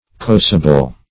Corrosible \Cor*ro"si*ble\ (k?r-r?"s?-b'l), a.
corrosible.mp3